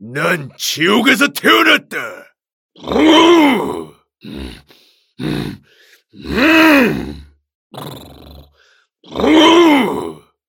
남자